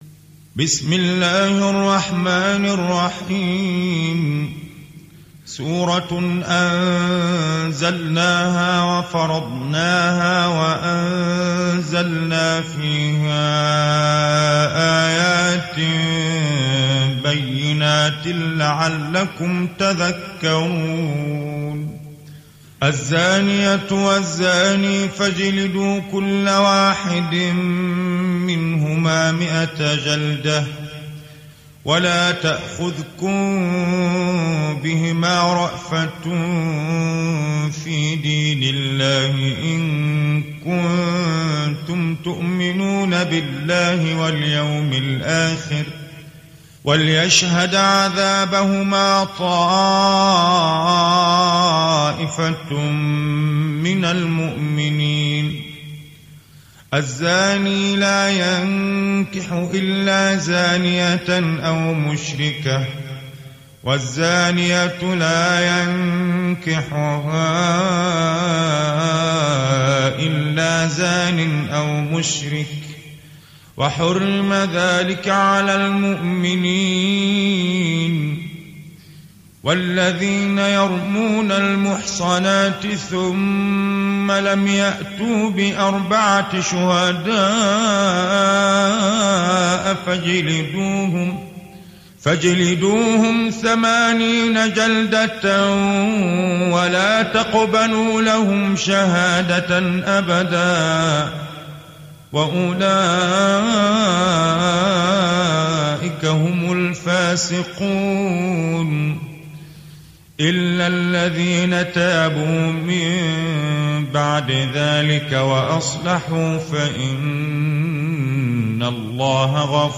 উপন্যাস Hafs থেকে Asim